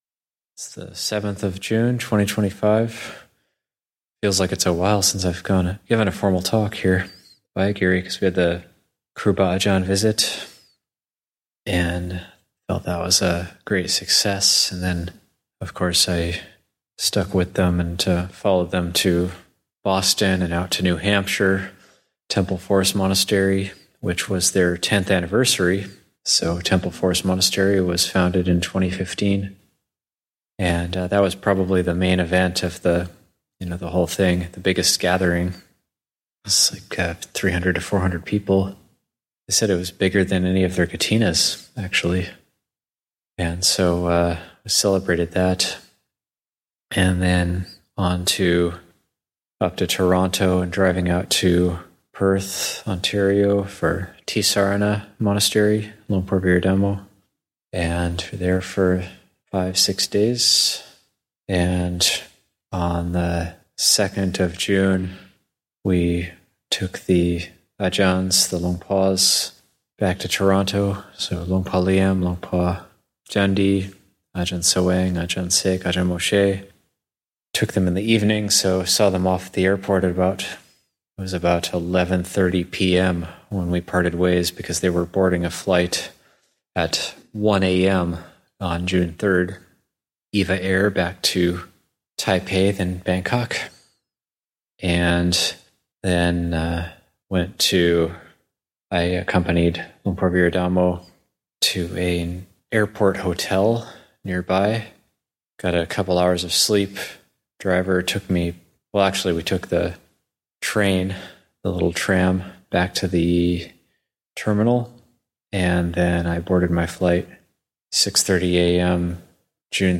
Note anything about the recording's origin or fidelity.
Dhamma Talks given at Abhayagiri Buddhist Monastery.